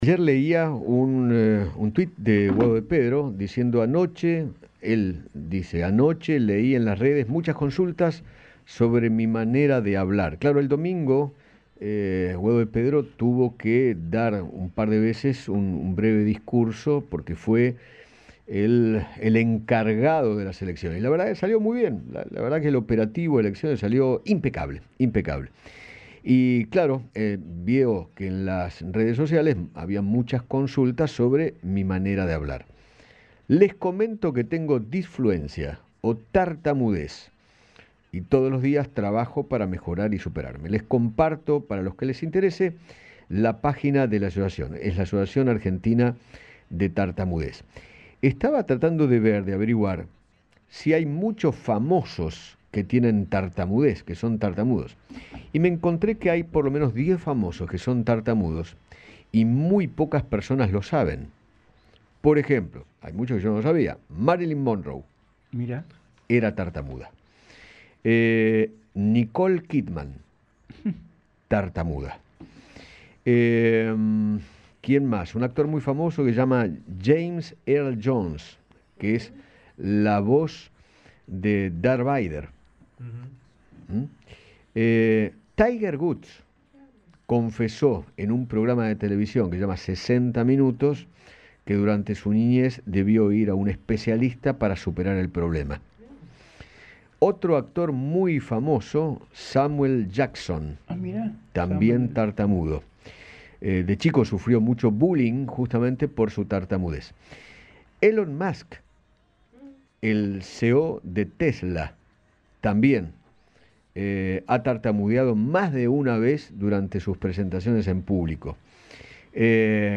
habló con Eduardo Feinmann y explicó los diferentes niveles de dicha condición